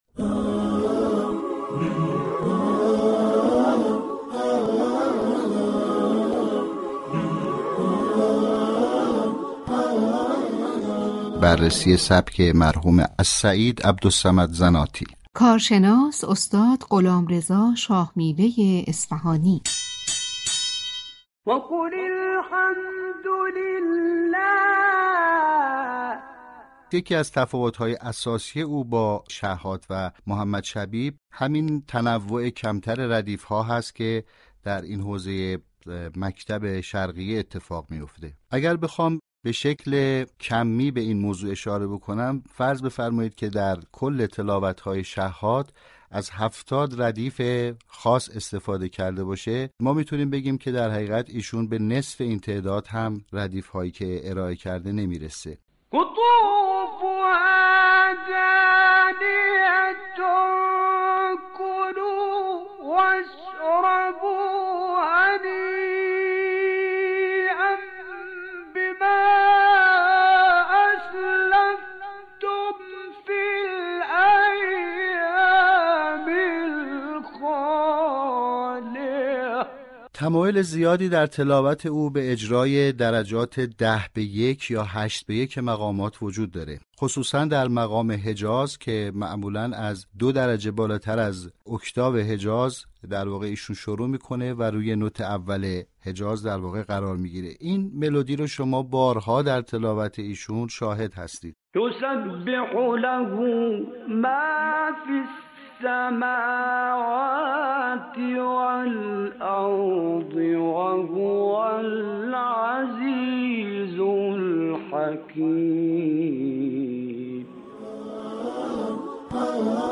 او توضیح داد كه زناتی اغلب آغاز مقام حجاز را دو درجه بالاتر از اوكتاو حجاز انتخاب كرده و روی نوت اول حجاز توقف می‌كرد، ملودی‌ای كه بارها در آثار او شنیده می‌شود.